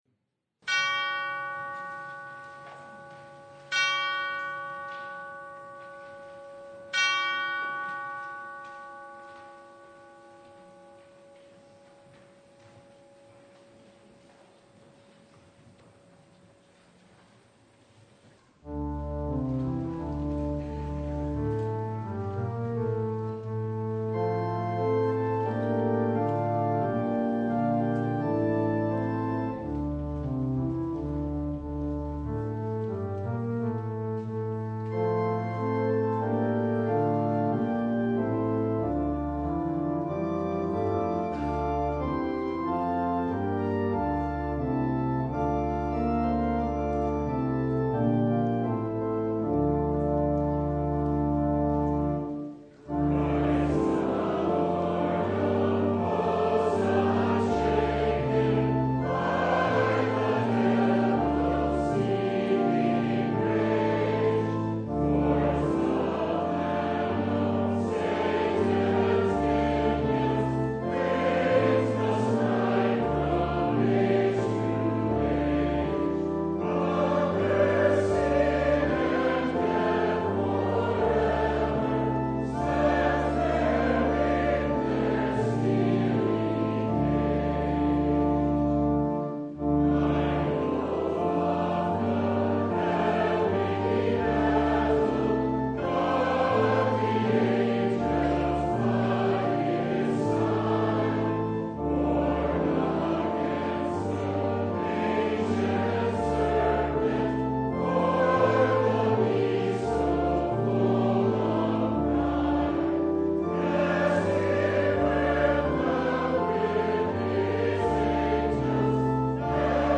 Passage: Luke 10:1-20 Service Type: Sunday
Full Service